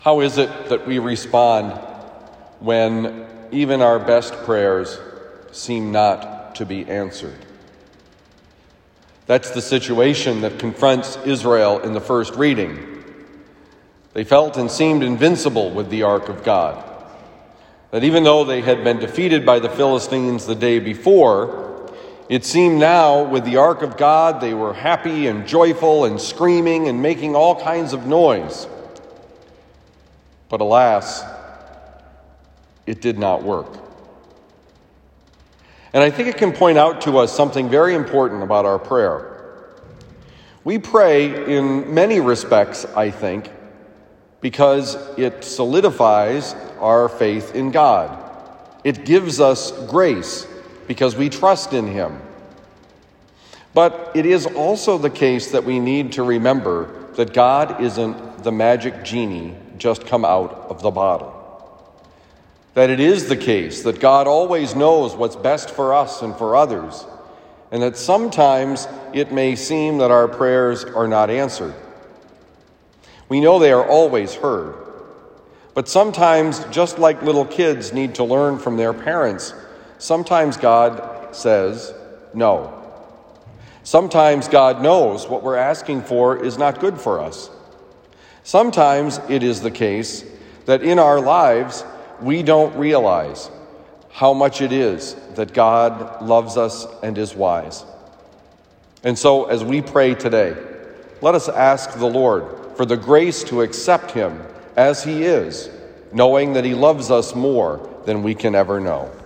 Homily given at Christian Brothers College High School, Town and Country, Missouri.